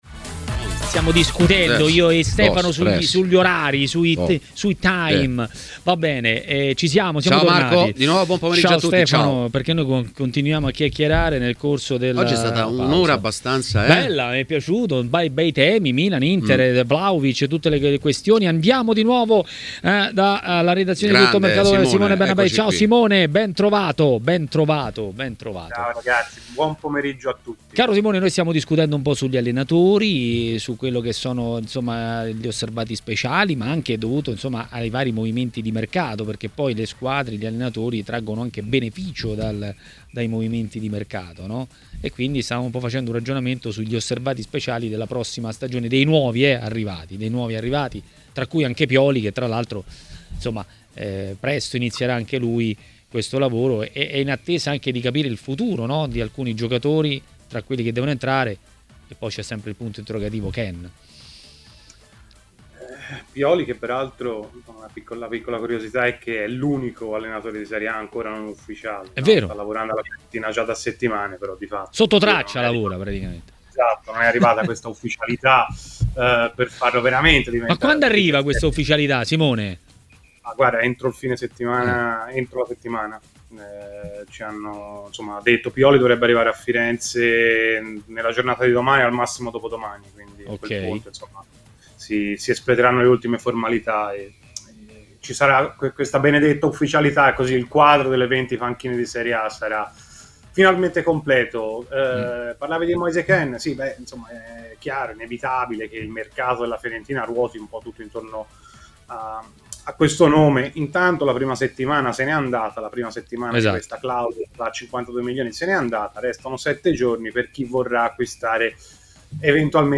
A parlare dei temi del giorno a TMW Radio, durante Maracanà, è stato l'ex portiere Simone Braglia.